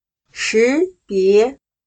识别/Shíbié/Distinguir, discernir; escoger.